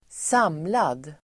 Uttal: [²s'am:lad]